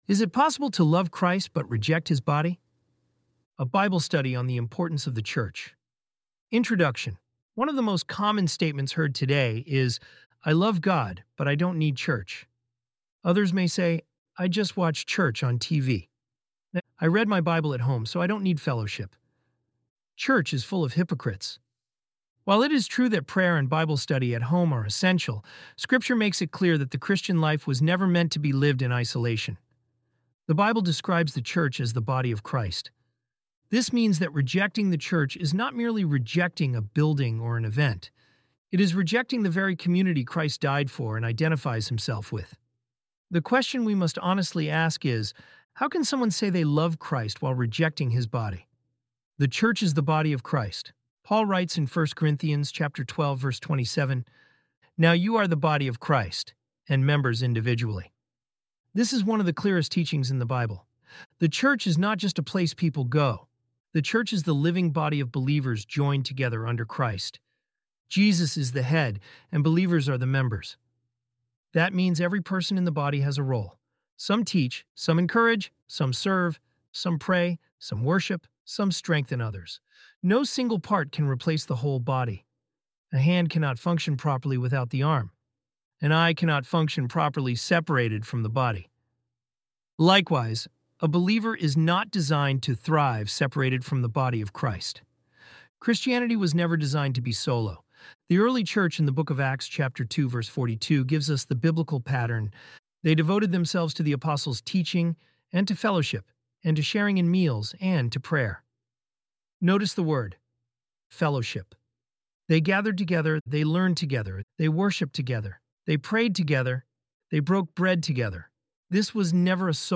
ElevenLabs_body.mp3